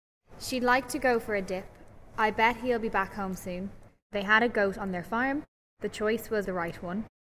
The first of the following recordings show this speaker reading out the sample sentences used for the survey. Here the speaker definitely has a non-local accent.
Switch-over accent with non-local features (GOAT-diphthongisation, R-retroflexion, CHOICE-raising)
SO_Sample_Sentences.mp3